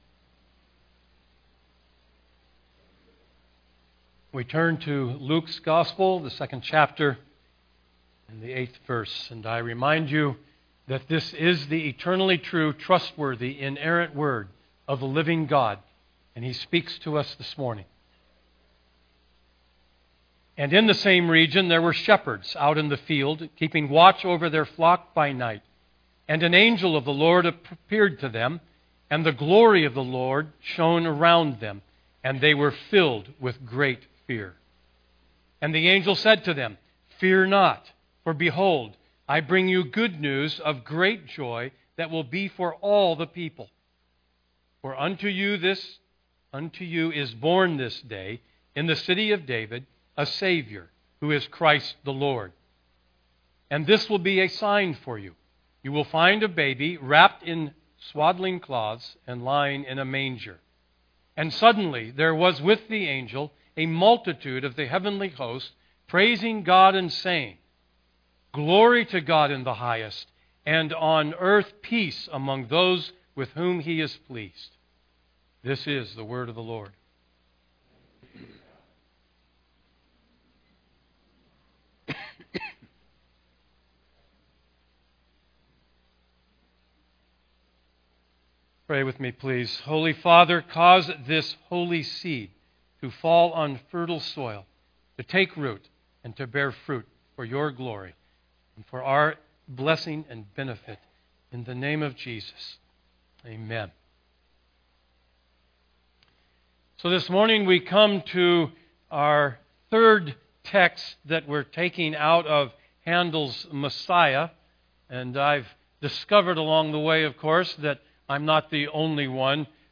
Download Sermon Notes Listen & Download Audio Series: Advent 2024 Preacher